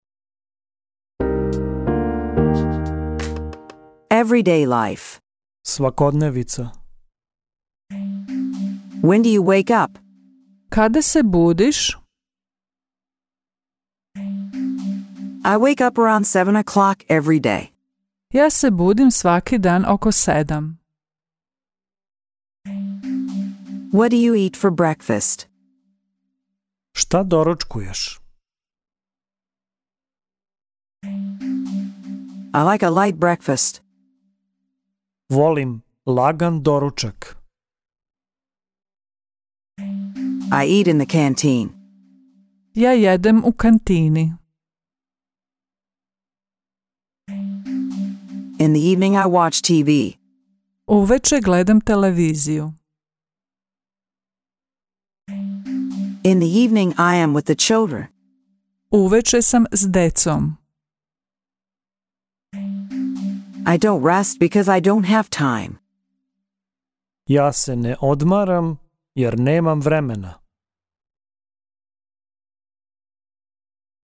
Audiobook in Serbian
The sentences are first presented in English, followed by the Serbian version and an inviting pause to repeat the sentence in Serbian.